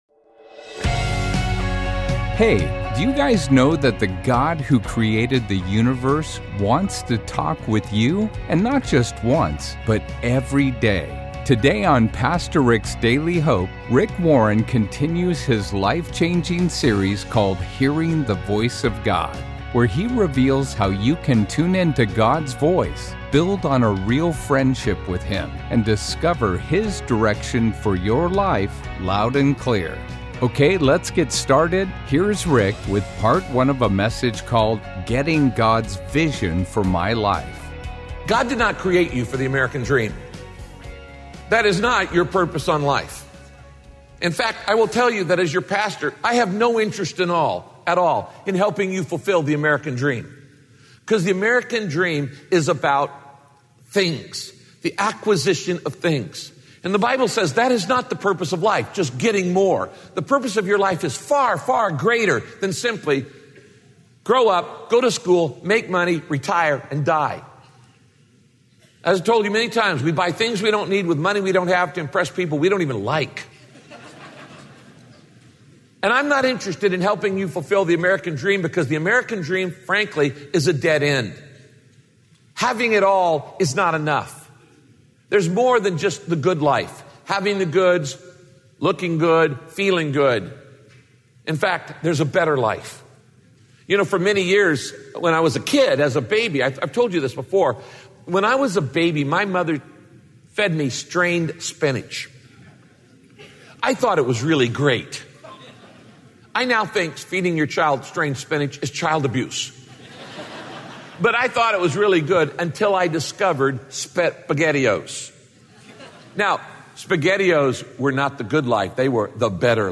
God doesn’t want you to just think about his dream for your life; he wants you to long for it! Join Pastor Rick as he teaches how to develop a passion for God, making you desperate to hear from him and honor him.